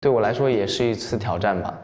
Text-to-Speech
Chinese_Audio_Resource / 蔡徐坤 /无背景音乐的声音 /对我来说也是一次挑战吧.wav